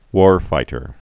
(wôrtər)